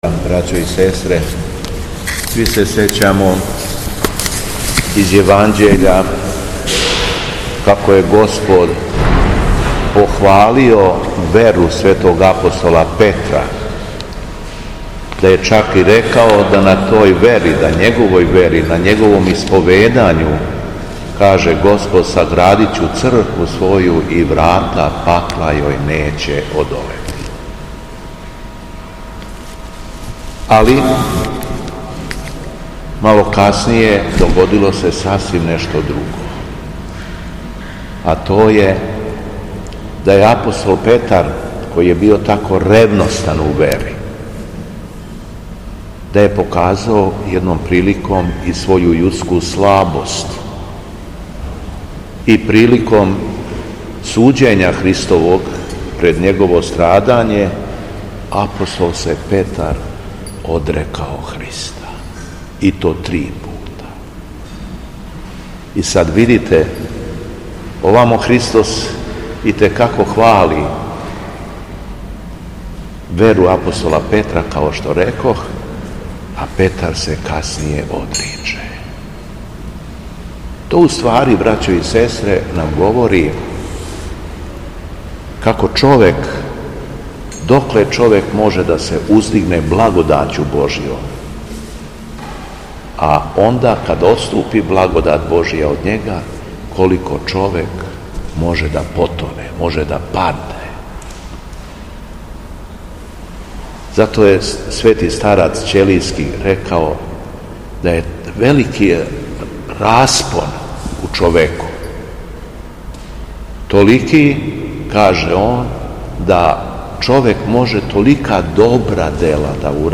Беседа Његовог Преосвештенства Епископа шумадијског г. Јована
Преосвећени се верном народу после прочитаног Јеванђеља обратио речима да: